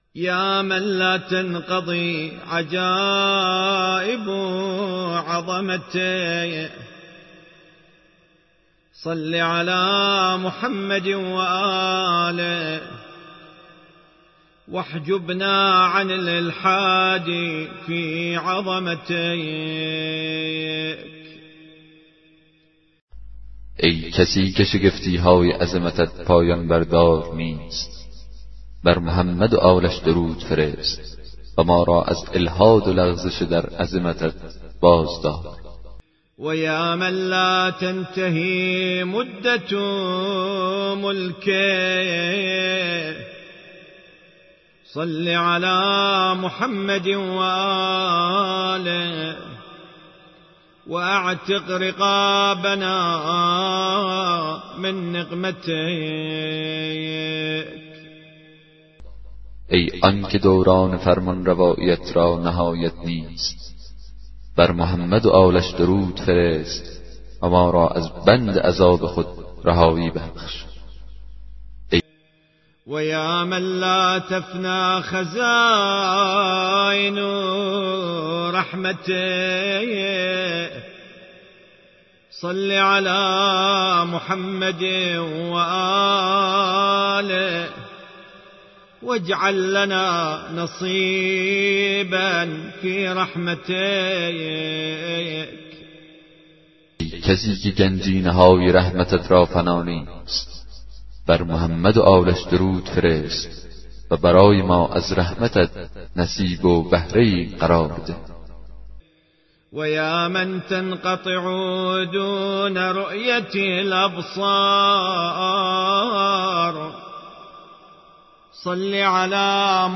دسته بندی : کتاب صوتی